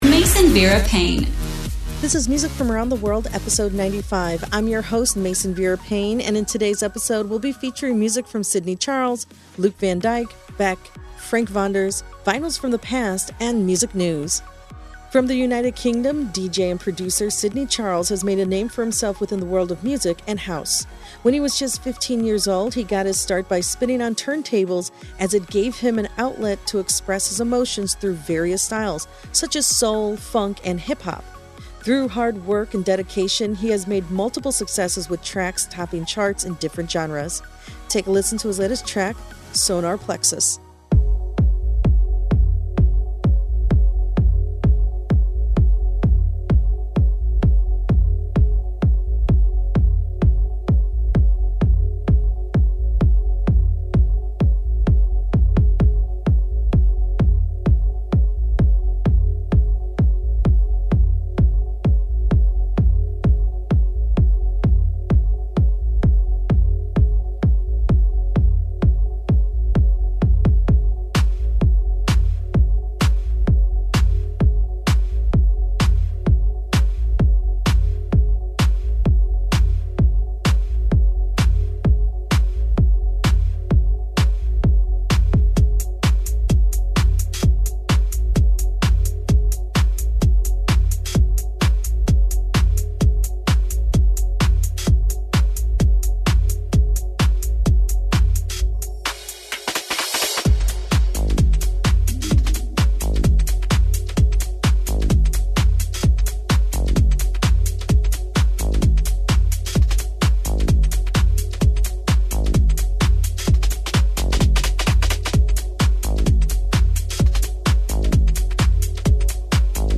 Explore genres from House to Trance.